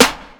He's Back Snare.wav